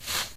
clothes2.ogg